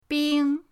bing1.mp3